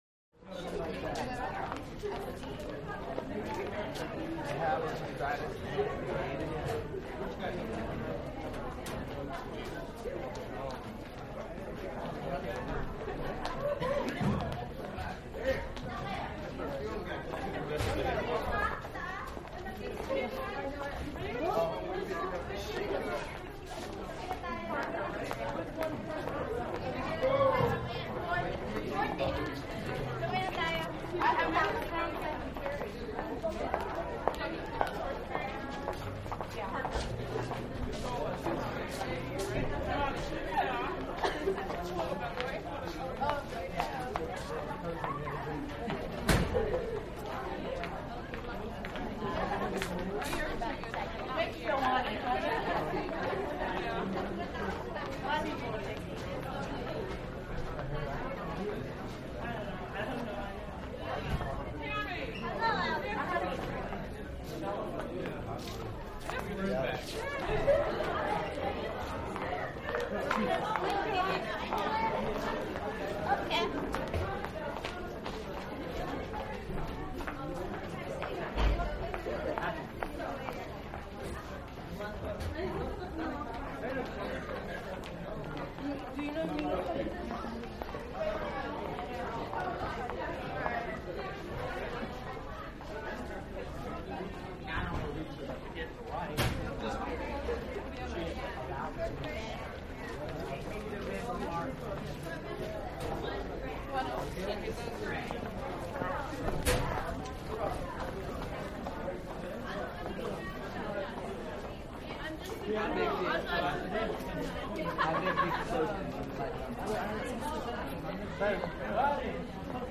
Hallway - School Between Classes